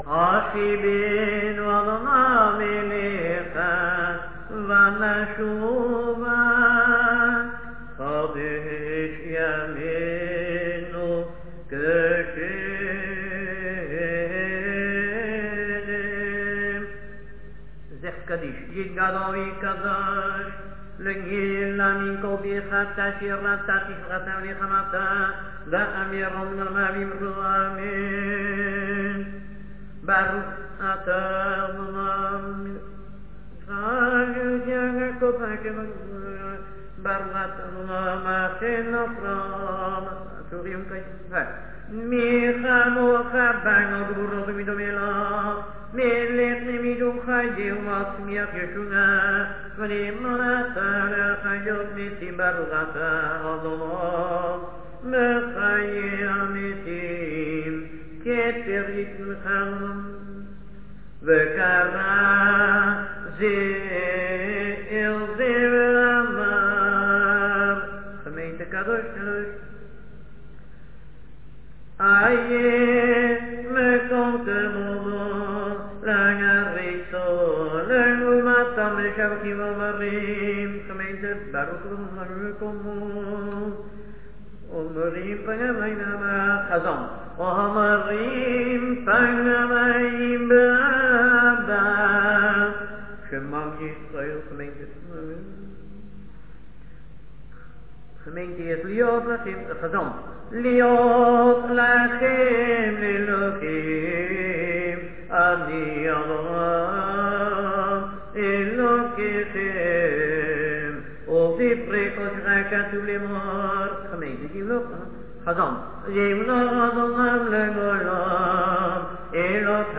All are standing during Kaddish.